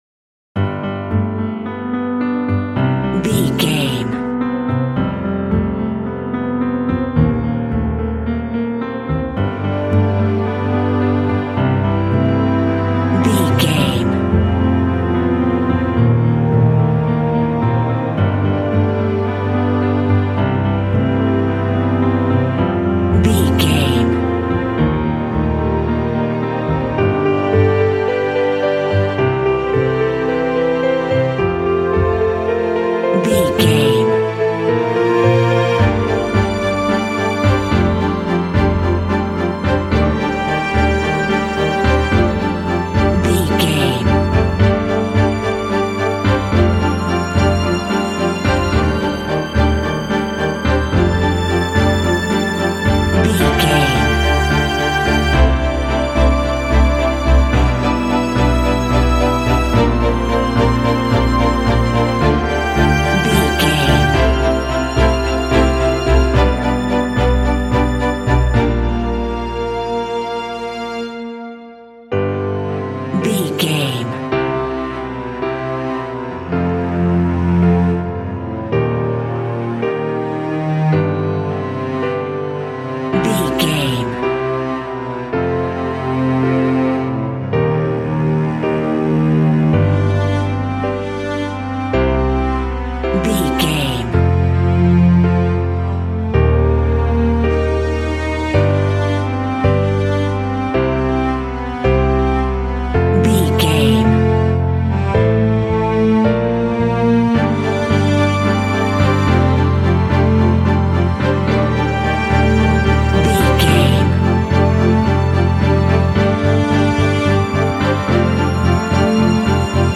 Ionian/Major
Slow
dreamy
foreboding
strings
piano
contemporary underscore